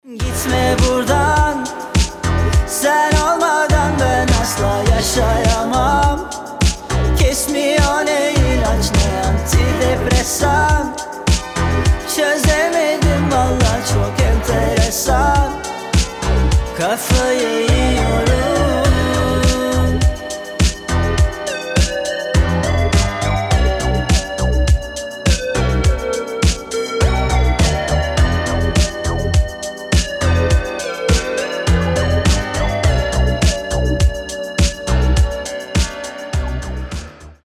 Немножко искажения накинул , вы же любите